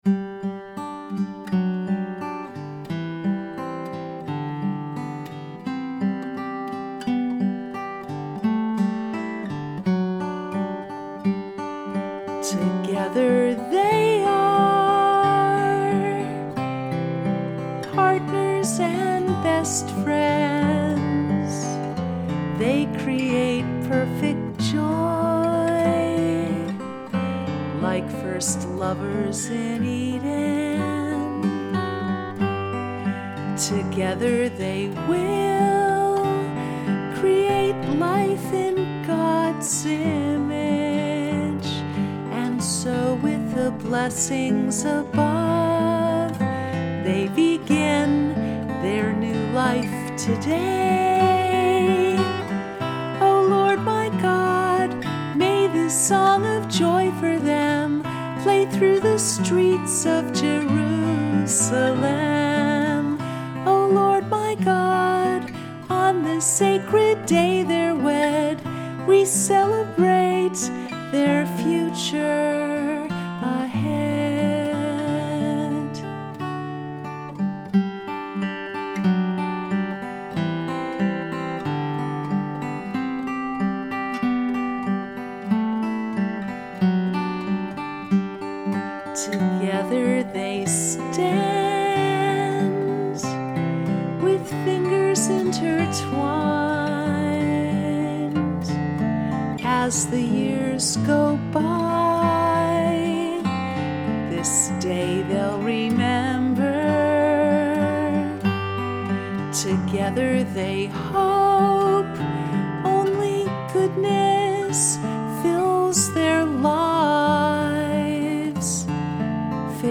Song of Joy Home Recording 7-20-16
song-of-joy-acoustic-mix-6-1.mp3